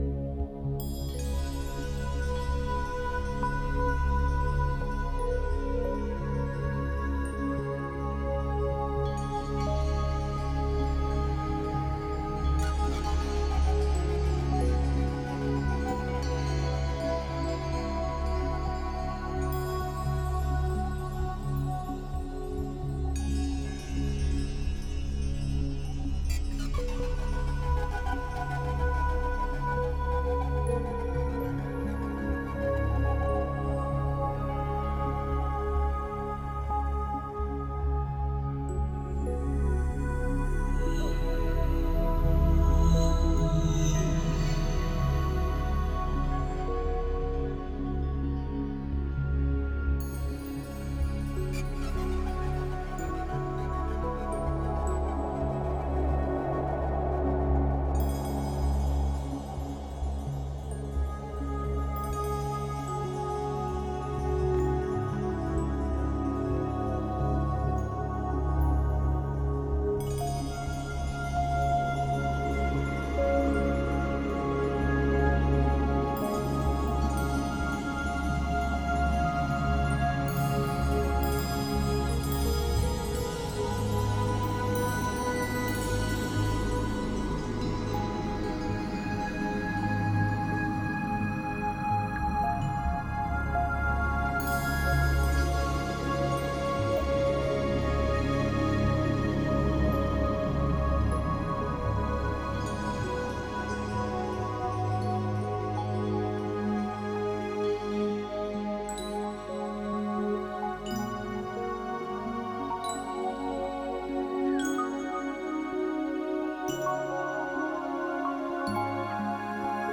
Жанр: Soundtrack.